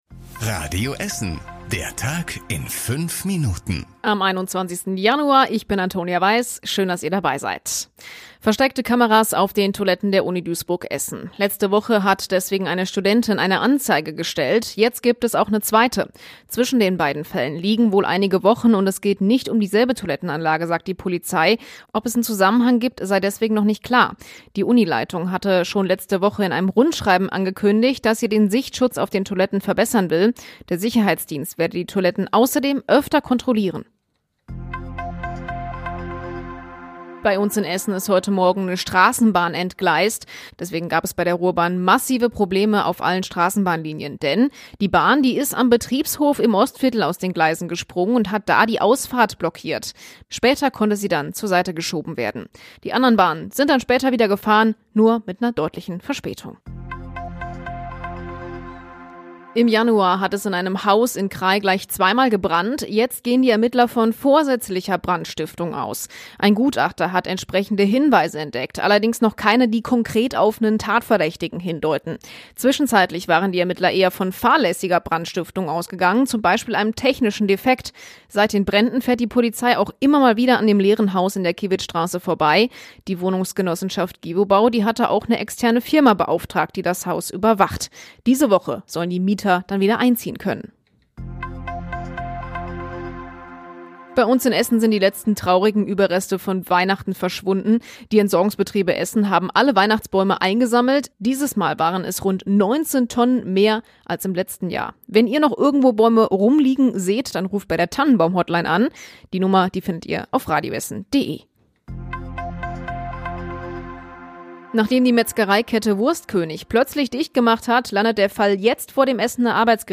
Unsere Nachrichtenredakteure fassen den Tag für Euch noch mal zusammen. Was war heute wichtig?
Täglich um 19.30 bei uns im Radio.